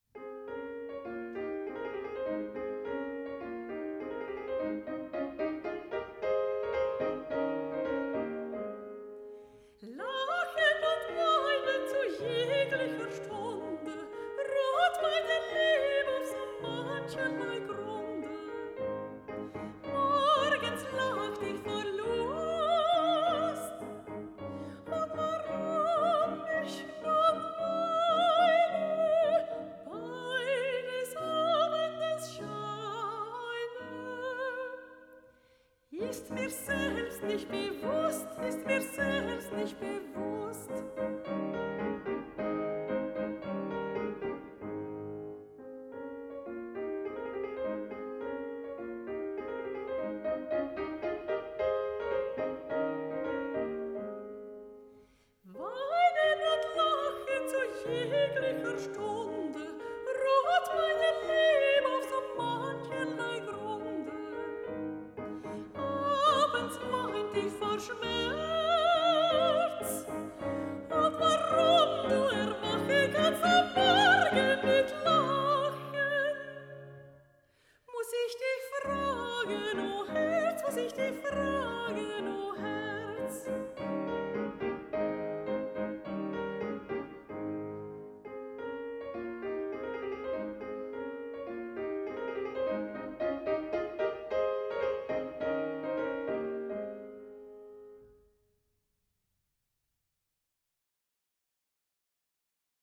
Klassik-, Musical- und Jazz-Melodien. Eine Stimme, die unter die Haut geht!